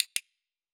Sound / Effects / UI / Minimalist9.wav